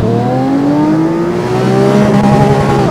Index of /server/sound/vehicles/vcars/lamboaventadorsv
second.wav